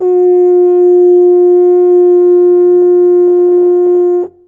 描述：F4在用Behringer B2PRO电容式大振膜麦克风录制的大号上演奏。该录音是麦克风对比测试的一部分。
Tag: 大号 F4 黄铜 冷凝器 音符